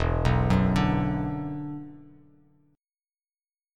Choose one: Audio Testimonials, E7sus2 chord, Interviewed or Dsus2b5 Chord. E7sus2 chord